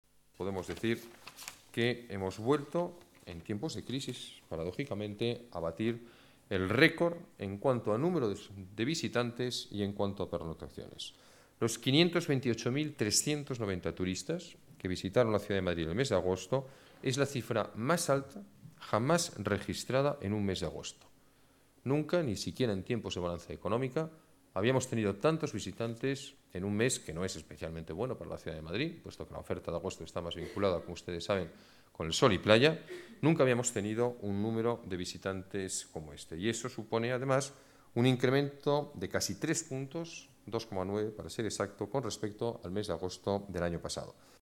Nueva ventana:Declaraciones del alcalde: cifras récord de turismo